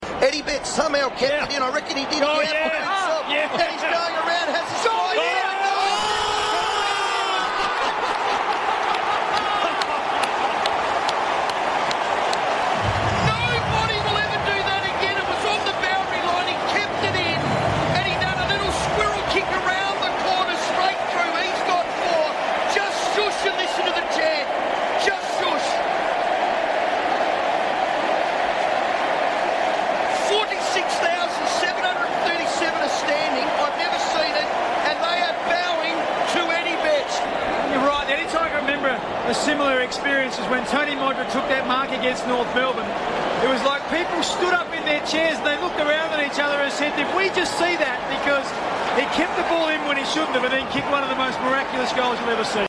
The FIVEaa crew call Eddie Betts' incredible goal against the Giants